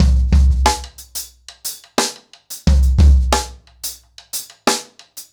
InDaHouse-90BPM.27.wav